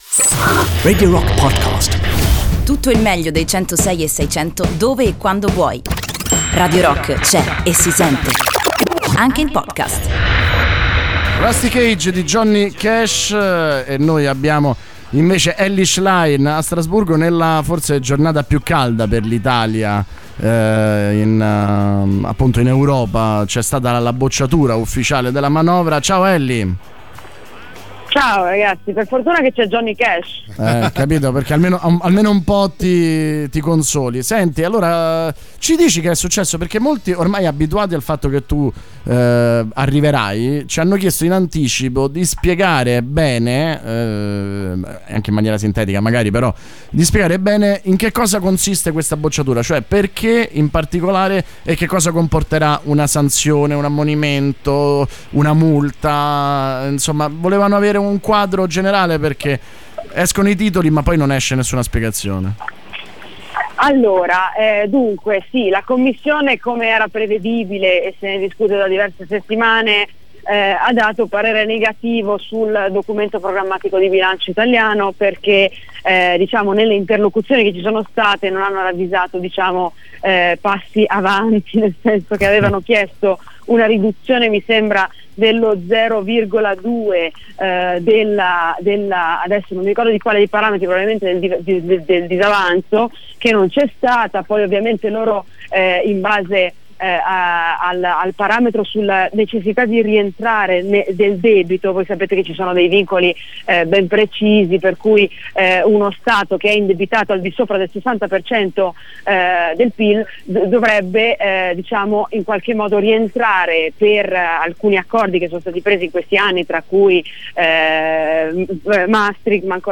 Intervista: Elly Schlein (21-11-18)